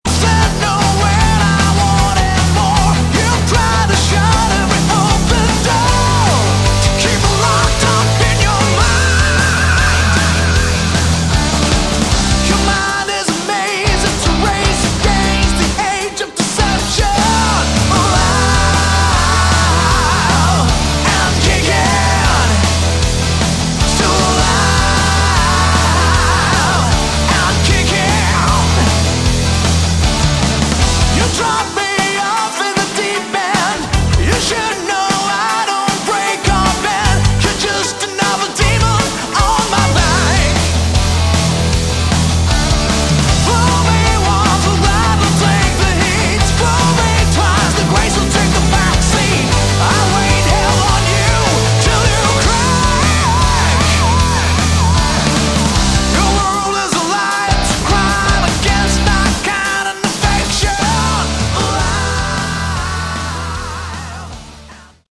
Category: Hard Rock
drums